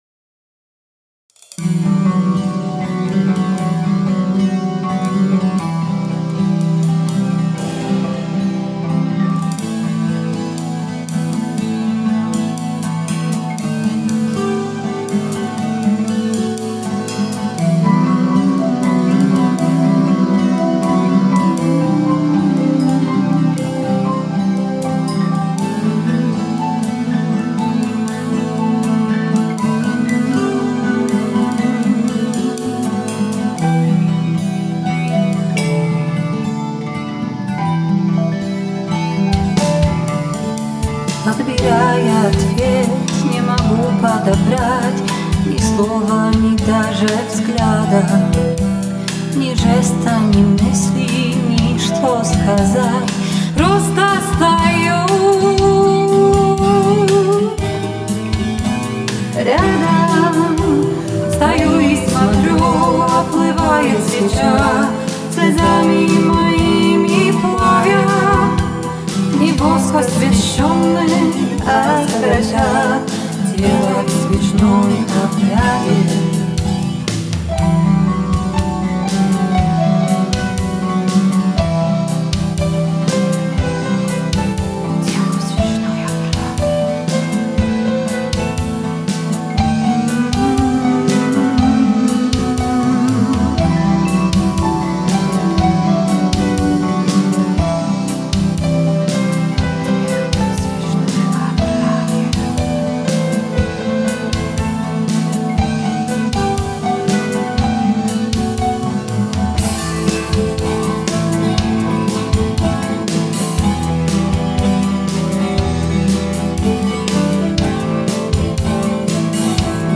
Для первого микса предложили исполнить вокальную партию